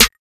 Snare SwaggedOut 2.wav